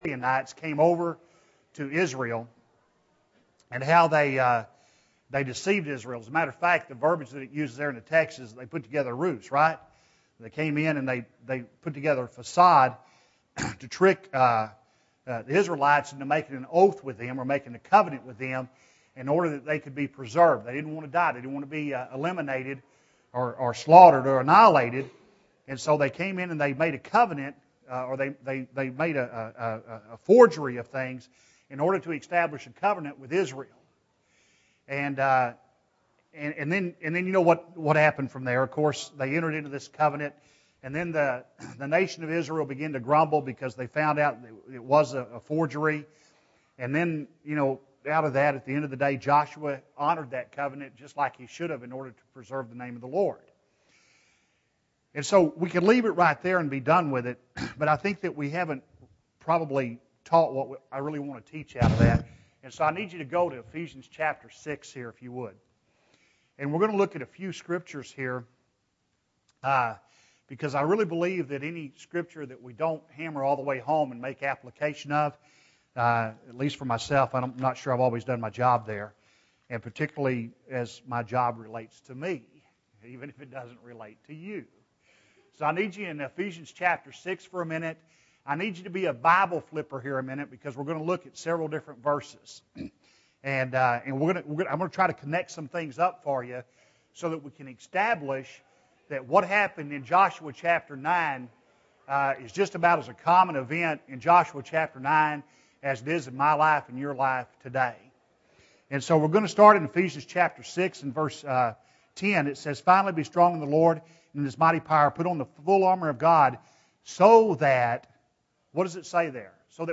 Joshua 10-14 (12 of 14) – Bible Lesson Recording